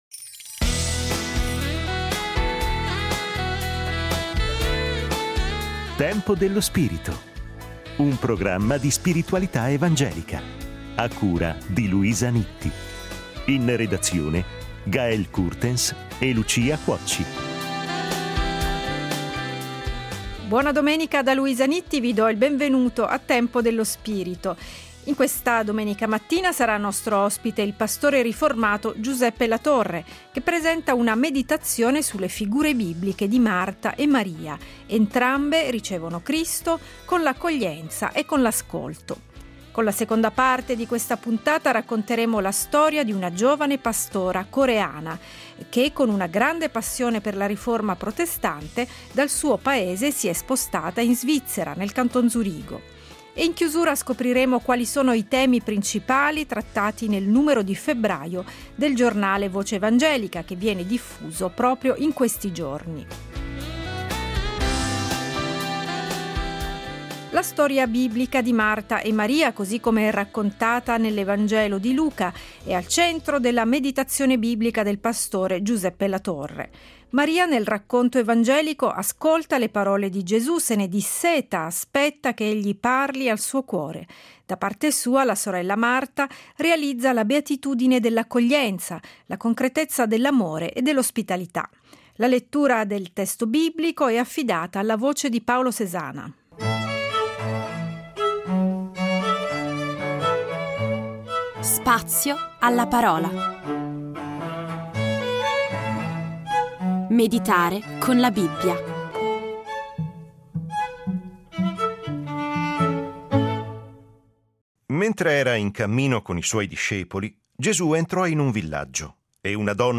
Scopri la serie Tempo dello spirito Settimanale di spiritualità evangelica.